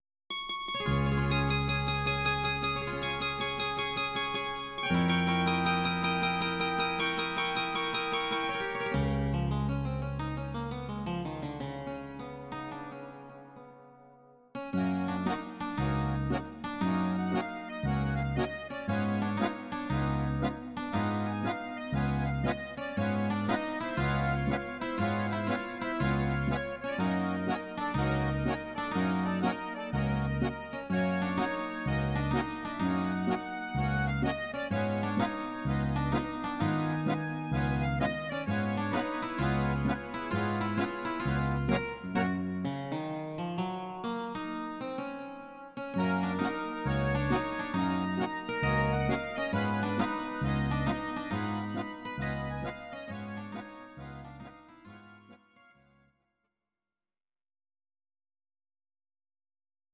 Audio Recordings based on Midi-files
Instrumental, Traditional/Folk, Volkst�mlich